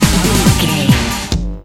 Uplifting
Aeolian/Minor
Fast
drum machine
synthesiser
electric piano
conga